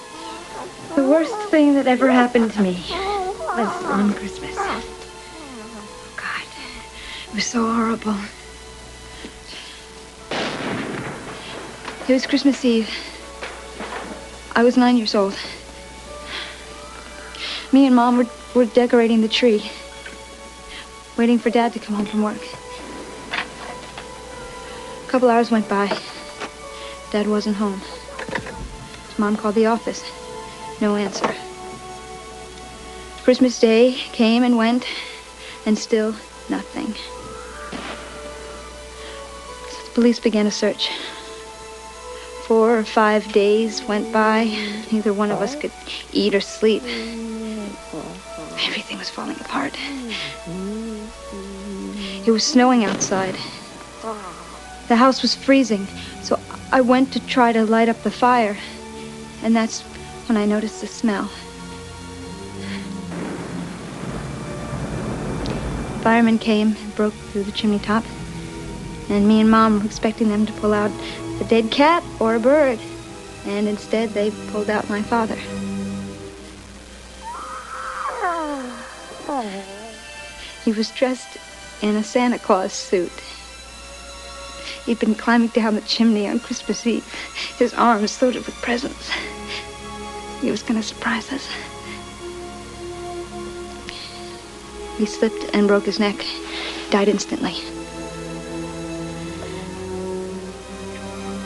Phoebe Cates gives a speech about why she hates Christmas that is sure to make any decent Christian child cry for no less than five minutes and emotionally scar them for life.